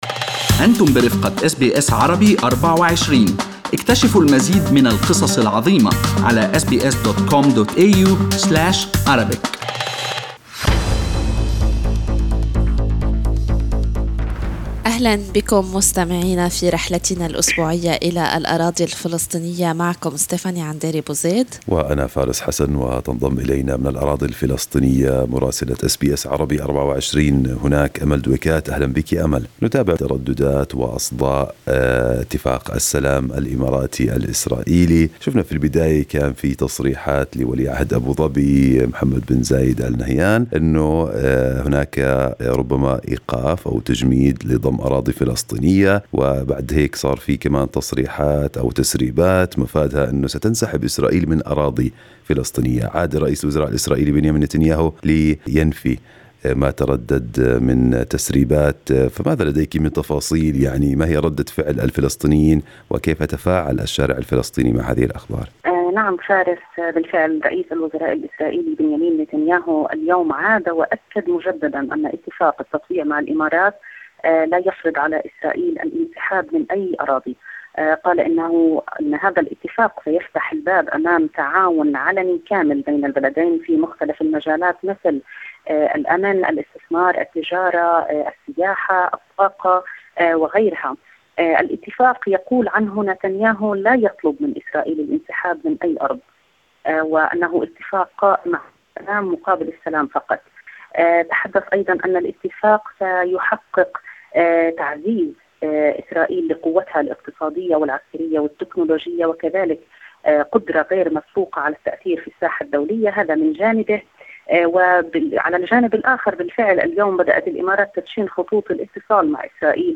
يمكنكم الاستماع إلى تقرير مراسلتنا في رام الله بالضغط على التسجيل الصوتي أعلاه.